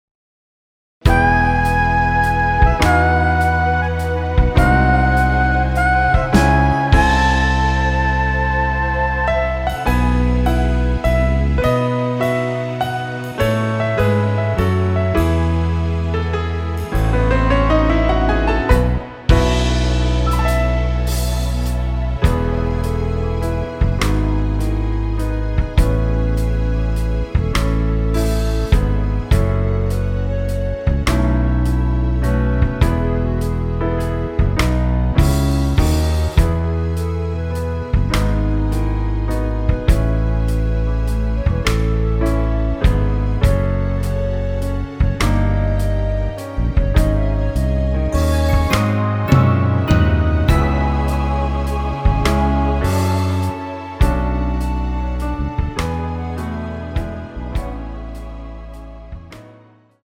원키에서(-3)내린 멜로디 포함된 MR입니다.(미리듣기 확인)
앞부분30초, 뒷부분30초씩 편집해서 올려 드리고 있습니다.
중간에 음이 끈어지고 다시 나오는 이유는